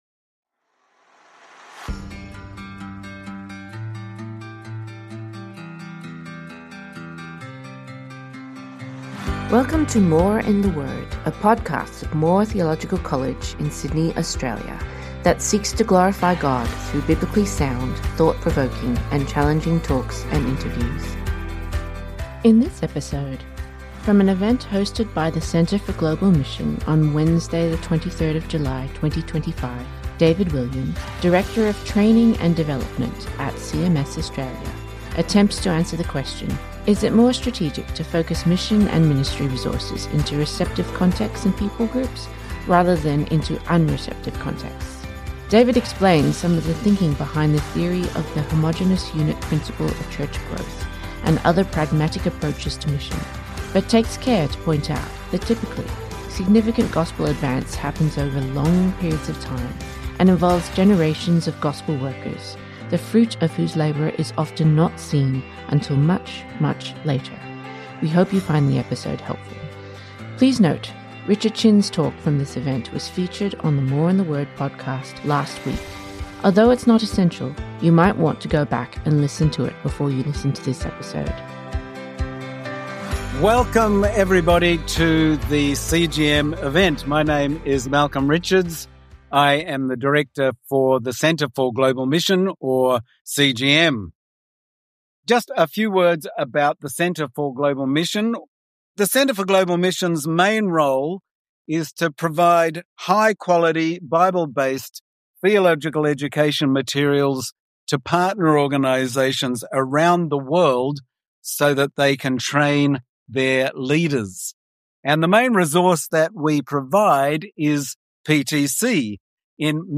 In this episode, from an event hosted by the Centre for Global Mission on Wednesday 23 July 2025